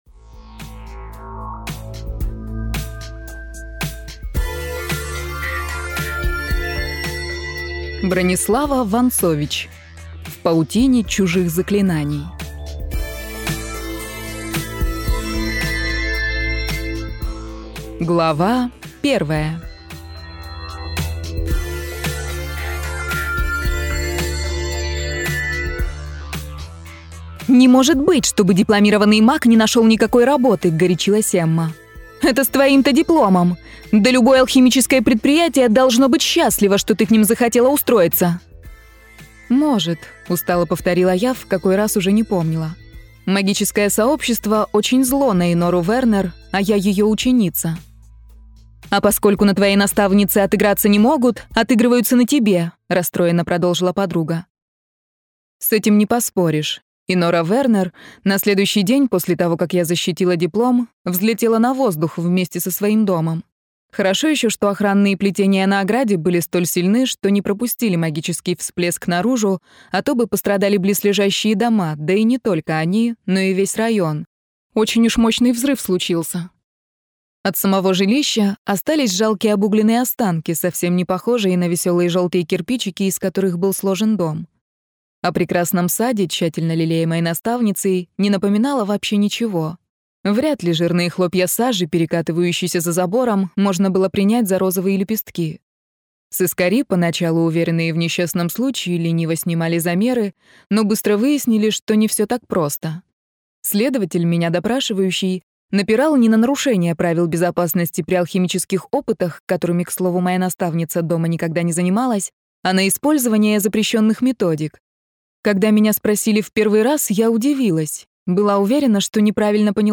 Аудиокнига В паутине чужих заклинаний - купить, скачать и слушать онлайн | КнигоПоиск